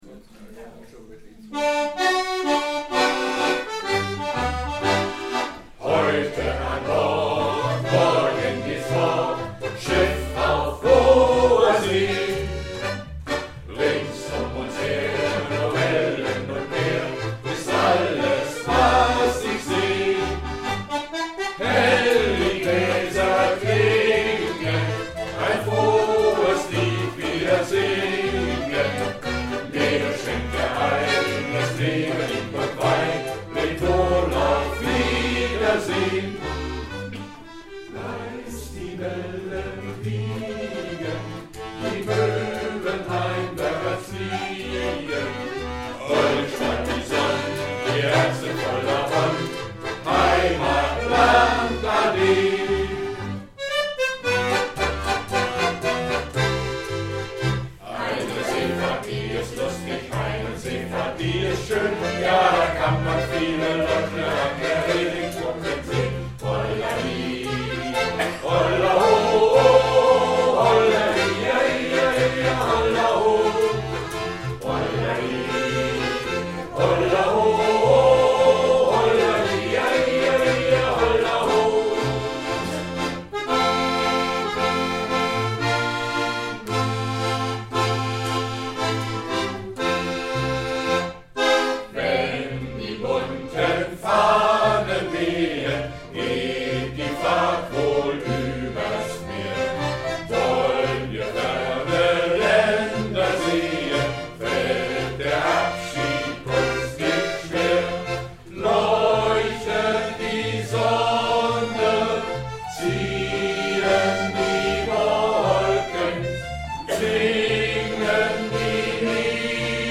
Shantys
Chor Chorgesang Jubiäum